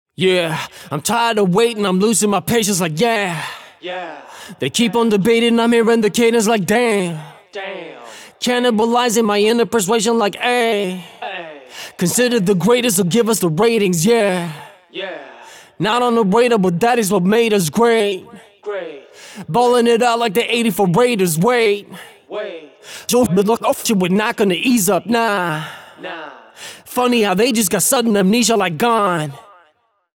Check out the examples below, the rap vocals were recorded in Soundtrap with a condenser mic.
Rapper before De-esser
No_De-esser-autotune.wav